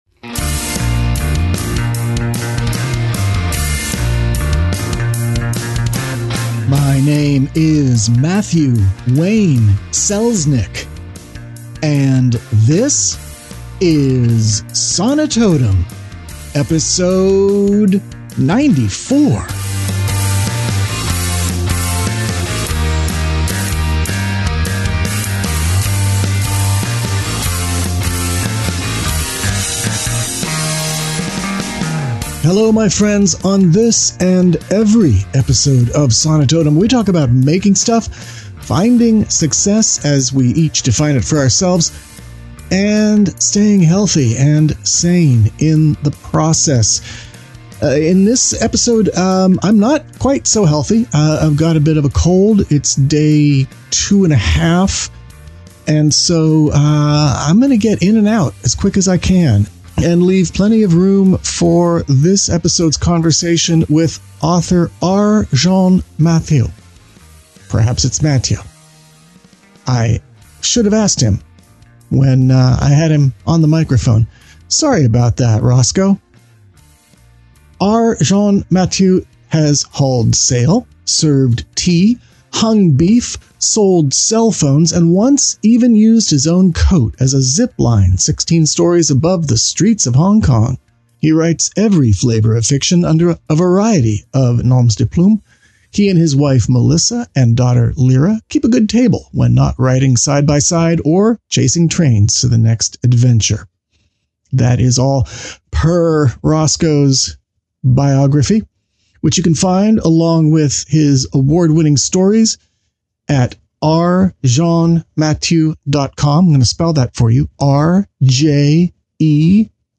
Sonitotum 094: In Conversation w